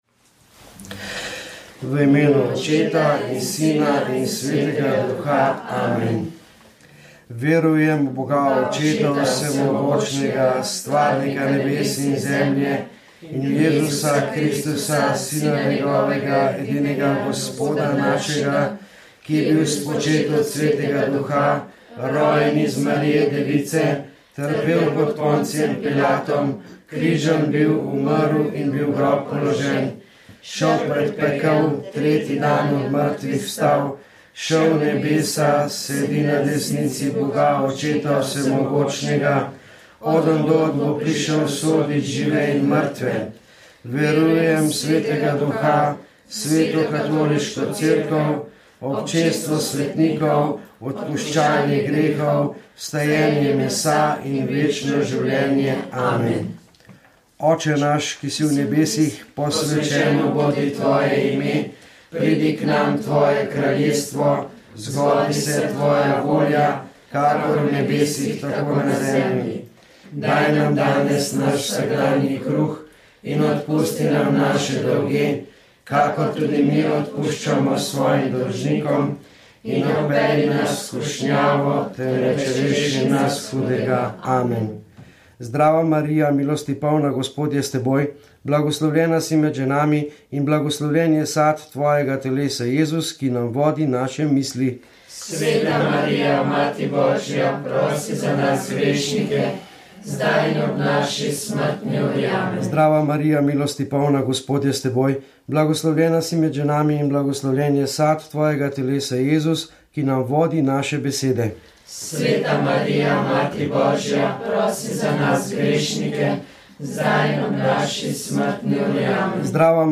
Molili so člani skupnosti Pelikan in Karitas.